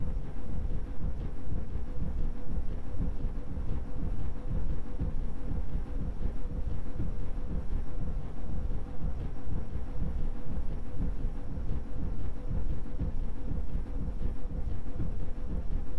pgs/Assets/Audio/Sci-Fi Sounds/Hum and Ambience/Machine Room Loop 6.wav at master
Machine Room Loop 6.wav